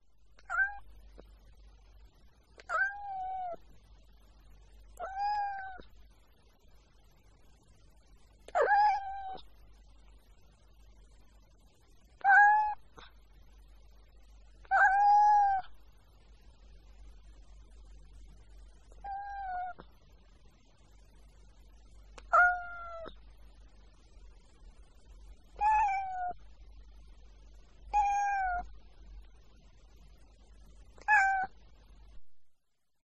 cat_meows.ogg